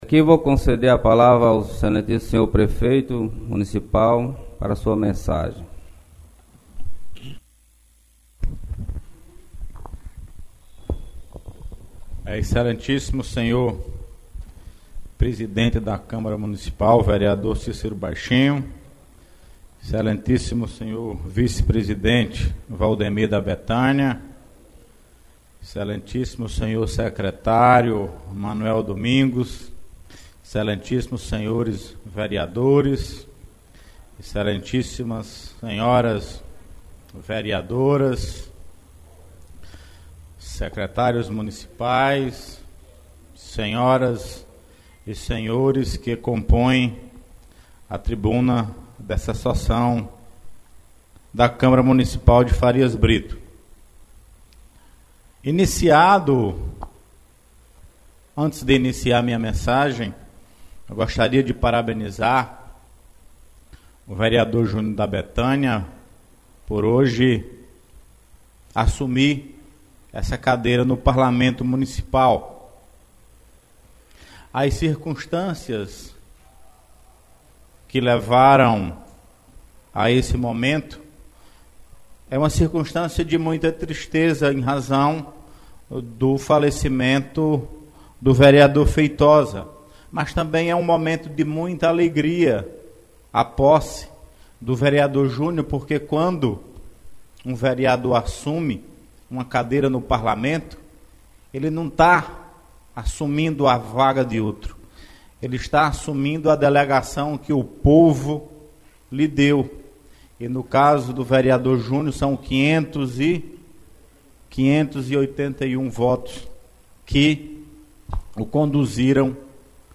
O Prefeito José Maria Gomes e o vice Dr. Cleber Mendes estiveram na manhã de ontem, 06/02, na Câmara Municipal de Farias Brito, em sua primeira sessão do ano, a fim de cumprimentar a nova gestão e dar as boas vindas aos vereadores para o exercício do ano de 2019, desejando a todos um ano muito produtivo. O Prefeito ressaltou ainda a relevância da continuidade da parceria entre a administração e a Câmara Municipal, que tantos benefícios já proporcionaram à cidade. Ouça as palavras do senhor prefeito.